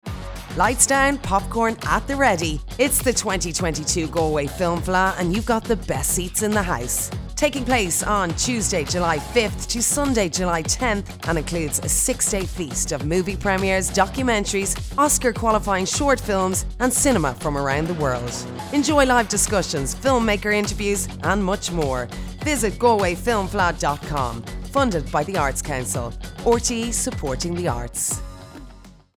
Female
Deep and a little husky!
30s/40s, 40s/50s
Irish Dublin Neutral, Irish Dublin Suburbs, Irish Neutral